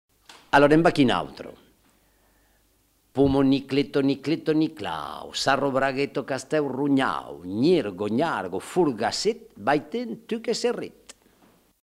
Aire culturelle : Haut-Agenais
Genre : forme brève
Effectif : 1
Type de voix : voix d'homme
Production du son : récité
Classification : formulette enfantine